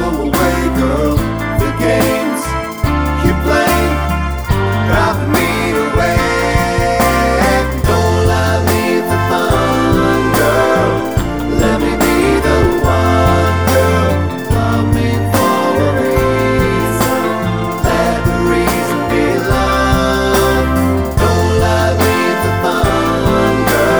Lower Key of F Pop (1970s) 4:04 Buy £1.50